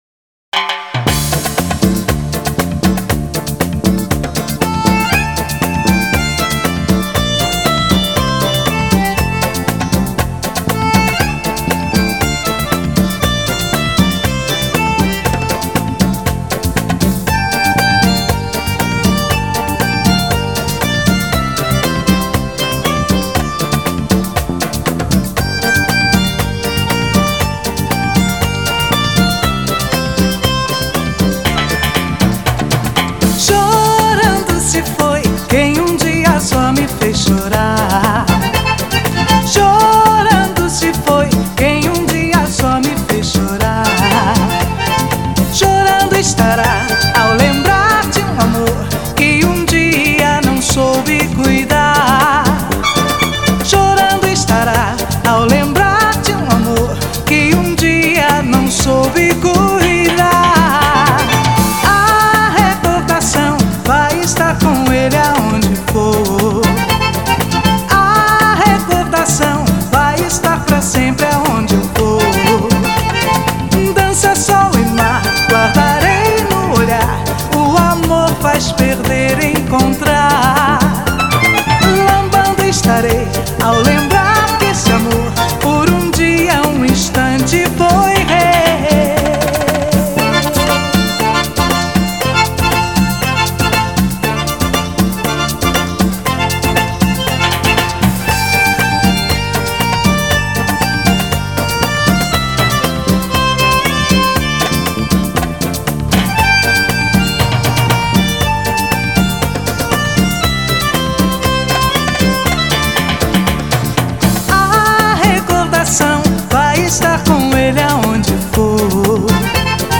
(Smart Phone)